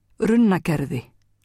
framburður